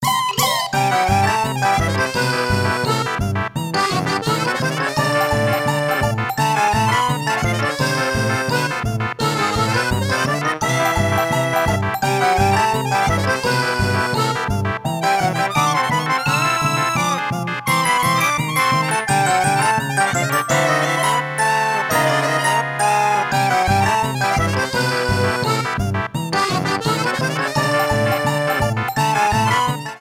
Results music
except this piece has an opening jingle.
Trimmed file to 30 seconds, applied fadeout